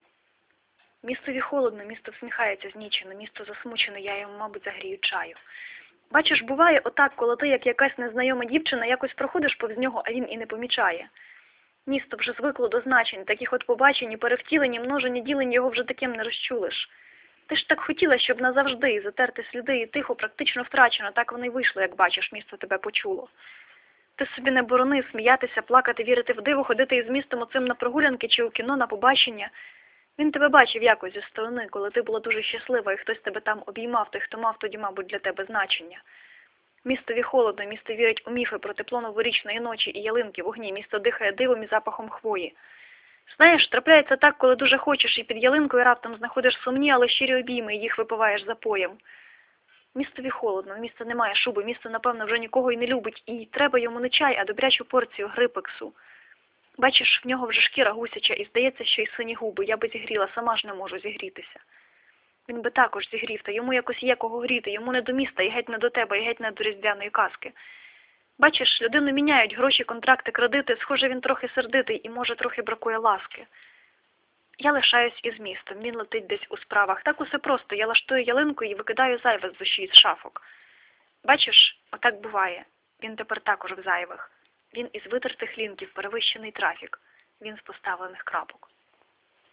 Кульне авторське читання!!!!!! friends 12 12 12